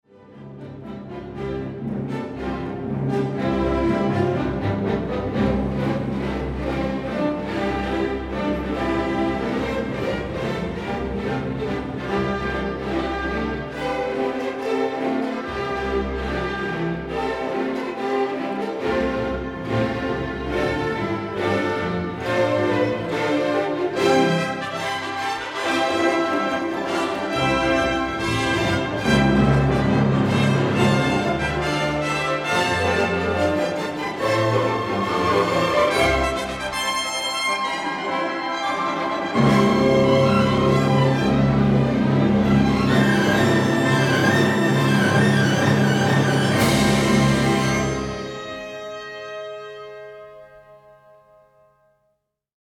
Z dwóch późniejszych o kilka lat nagrań koncertowych Kubelika znacznie bardziej znane jest to ponownie zarejestrowane z Bawarczykami w 1976 roku i wydane przez firmę Audite.
Także finał Kubelik prowadzi bardzo szybko, z werwą i energią. W przeciwieństwie do poprzedniego nagrania studyjnego wiele tu życia i humoru:
Kubelik7GMLive7.mp3